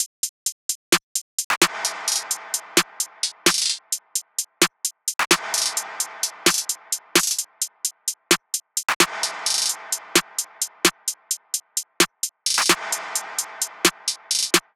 SOUTHSIDE_beat_loop_purple_top_02_130.wav